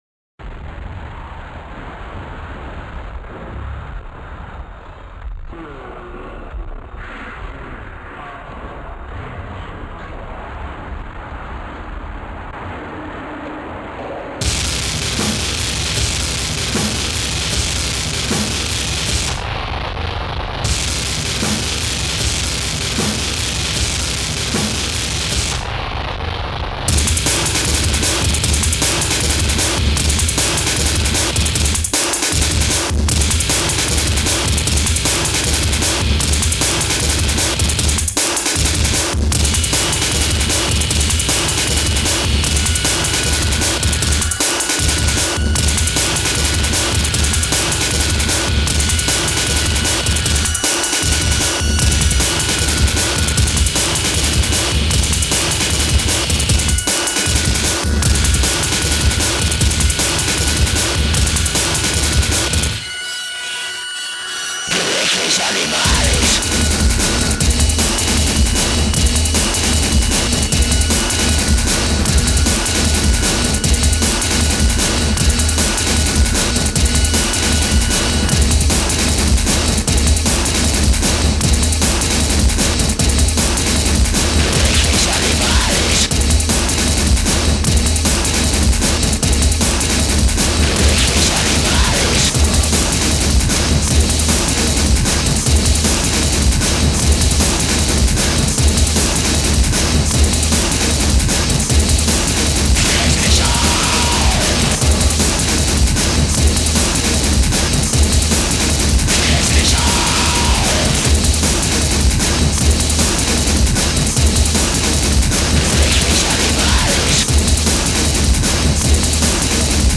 Du bon gros industrial qui remet les choses à leur place!